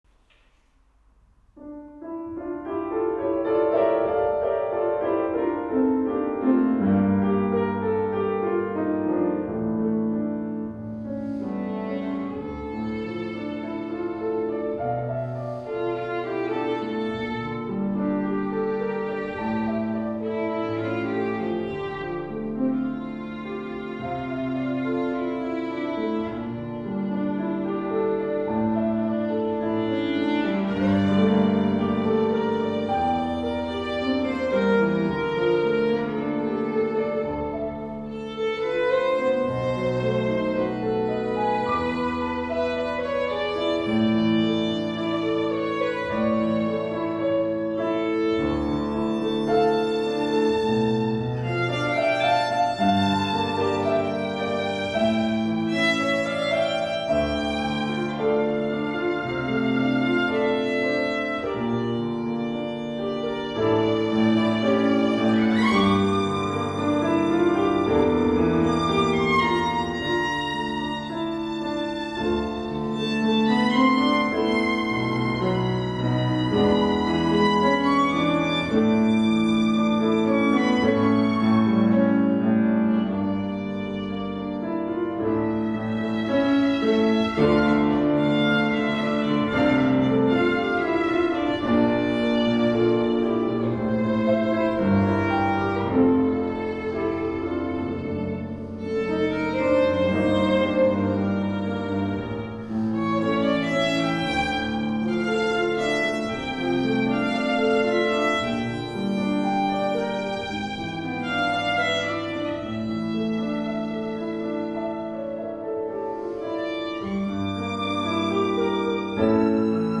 Details / Download: October Song (Fassung für Violine und Klavier)
Ein opulenter Titel für fortgeschrittene Spieler.